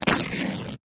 Звуки дивана
На этой странице собраны разнообразные звуки дивана: от скрипов старых пружин до мягкого шуршания обивки.